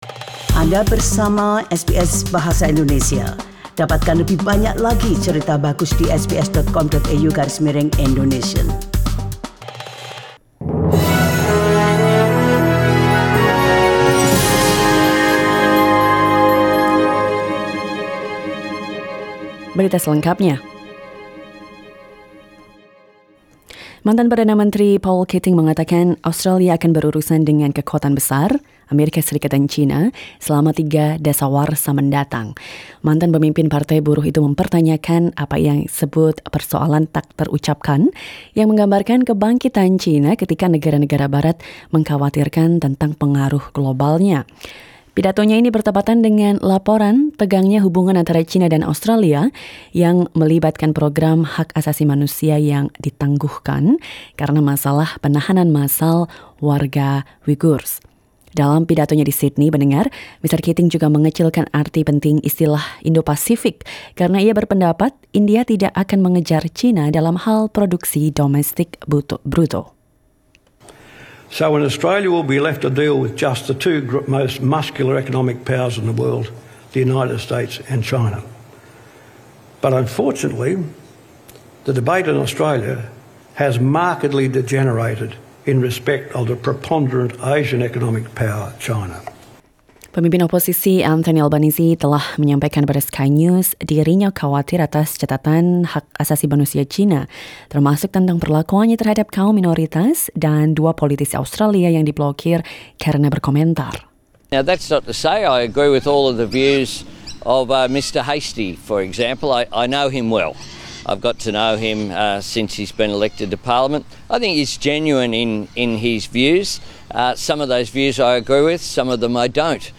SBS Radio news in Indonesian - 18 November 2019